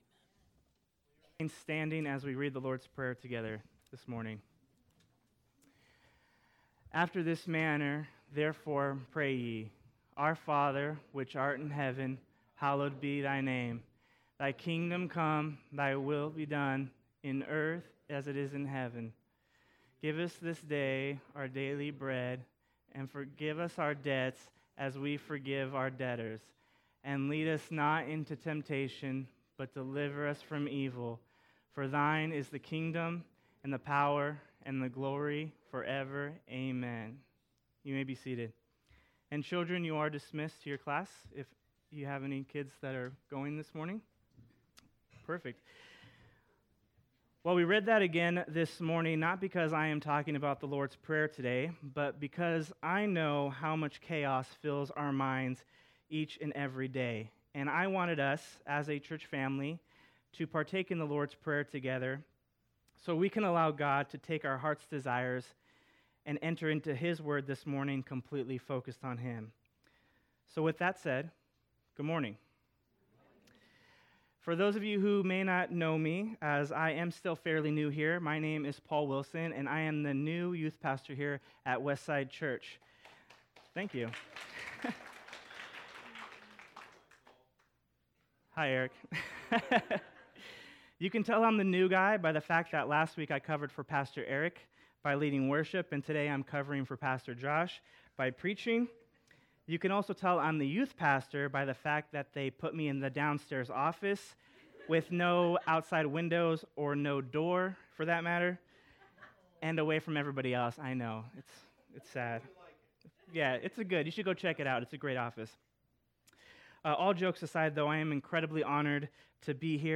Series: Special Sermon